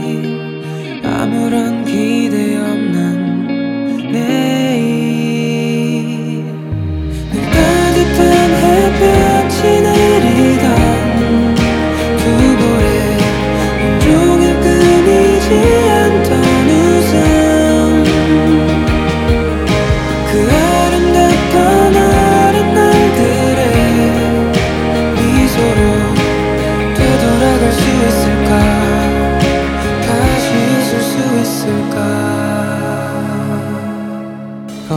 Жанр: Поп / K-pop / Музыка из фильмов / Саундтреки